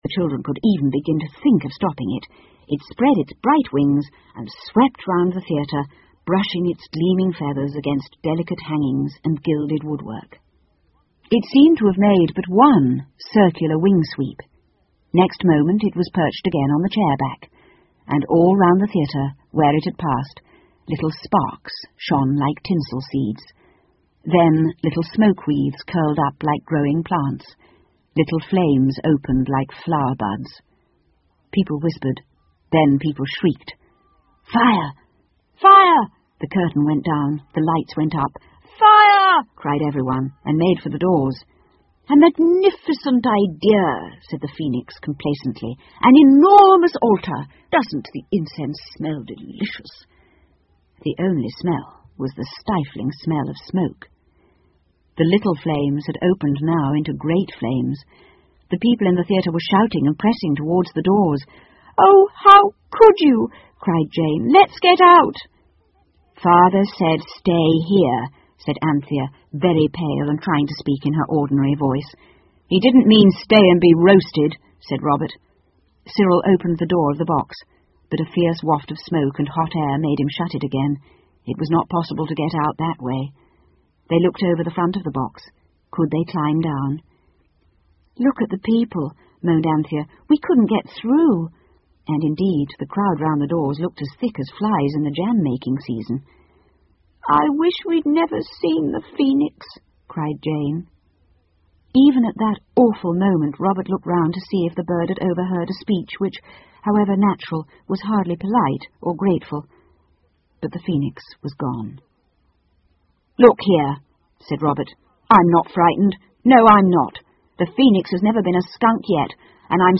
凤凰与魔毯 The Phoenix and the Carpet 儿童英语广播剧 13 听力文件下载—在线英语听力室